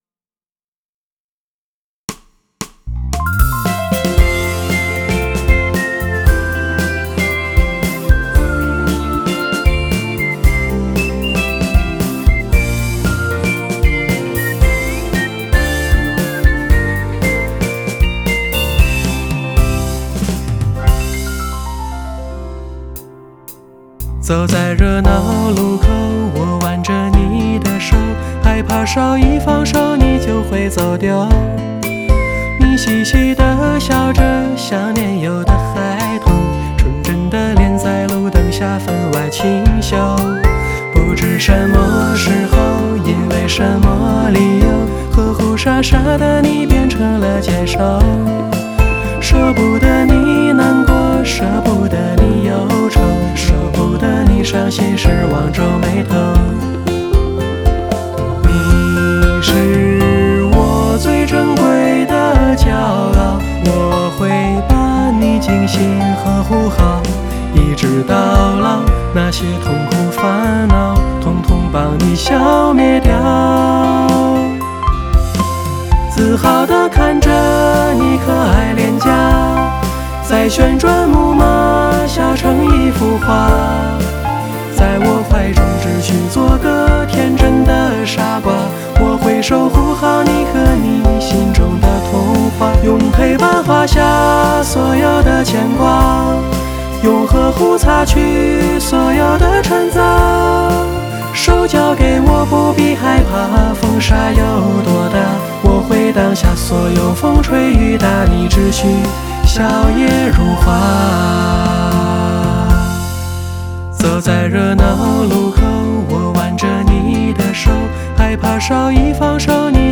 Here, I'd like to share with you a R&B love song that I composed and sang, titled "Fairy Tale".